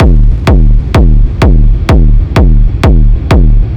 • Industrial kick 2134.wav
Industrial_kick_2134_XmN.wav